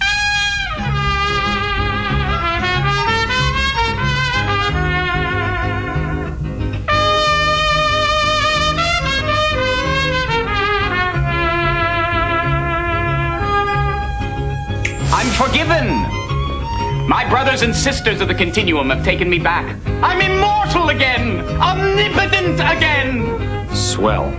Mariachi band